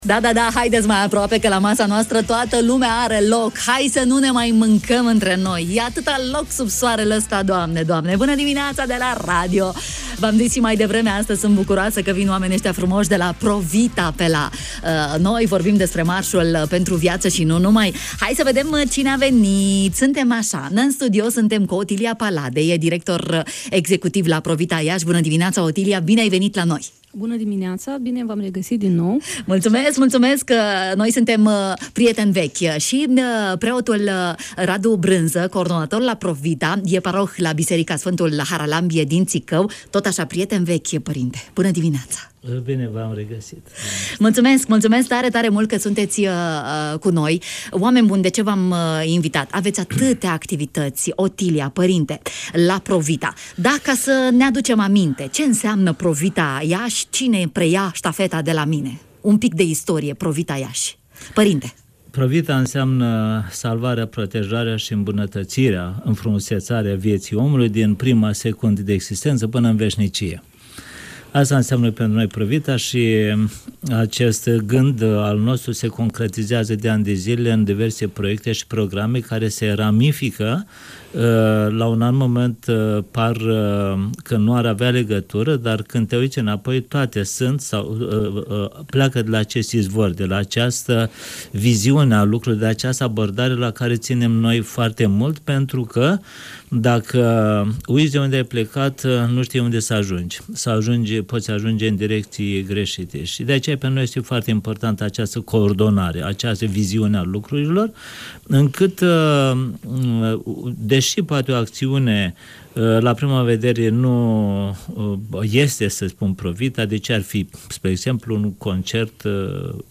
în direct la matinatul de la Radio România Iași